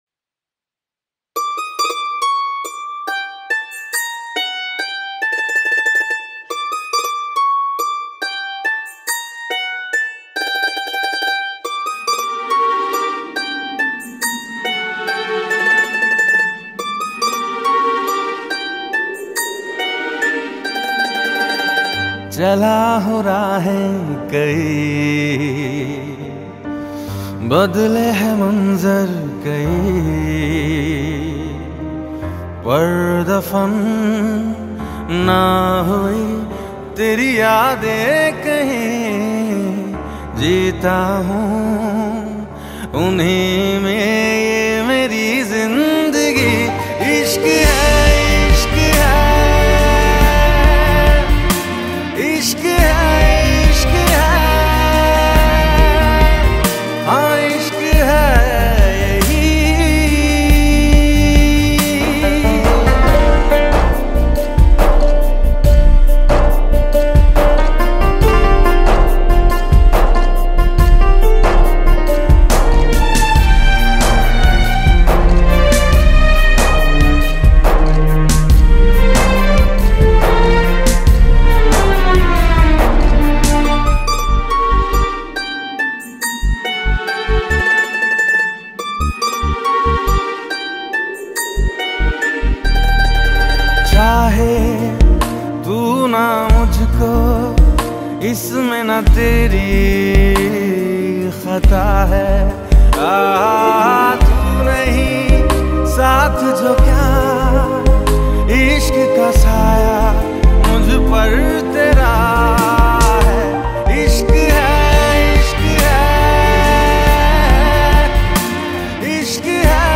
Sad Romantic Songs